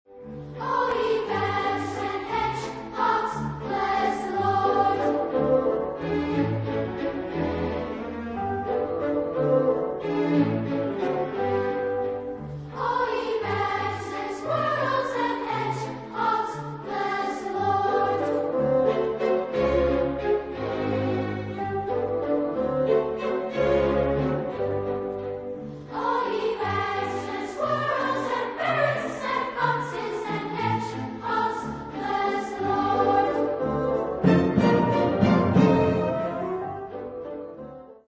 Epoque: 20th century
Type of Choir: SSA  (3 children voices )